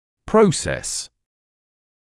[‘prəuses][‘проусэс]процесс, ход развития; отросток (анат.)coronoid process венечный отросток